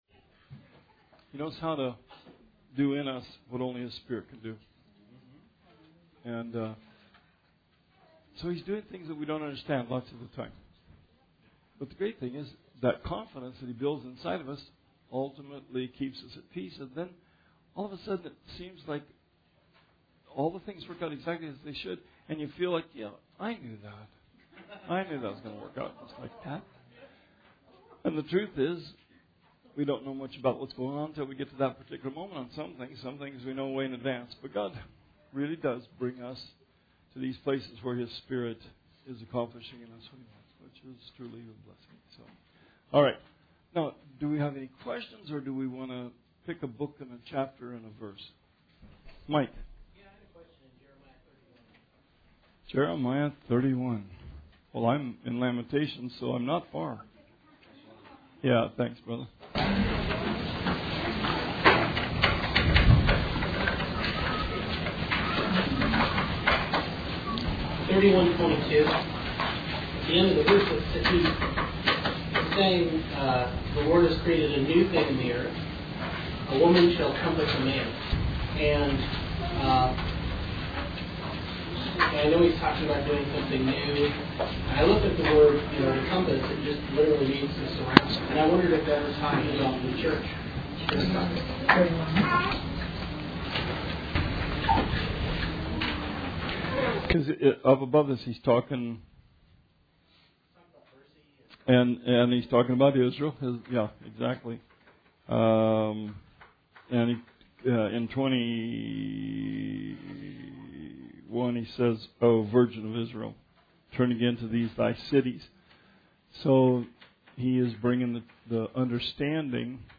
Bible Study 8/1/18